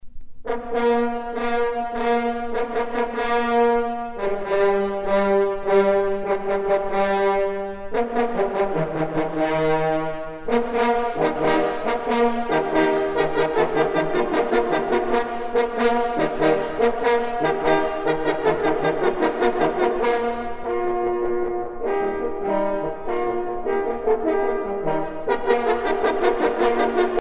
trompe - Fanfares et fantaisies de concert
circonstance : vénerie
Pièce musicale éditée